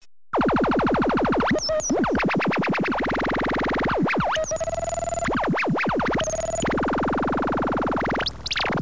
spacegun.mp3